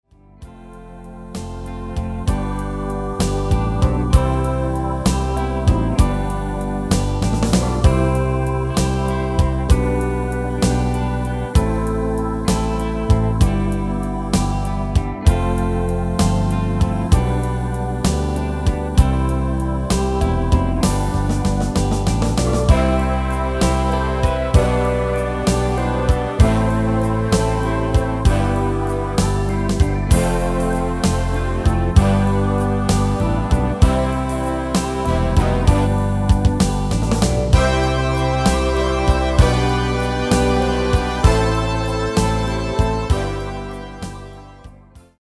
Demo/Koop midifile
Genre: Ballads & Romantisch
Toonsoort: C
- Géén vocal harmony tracks
Demo's zijn eigen opnames van onze digitale arrangementen.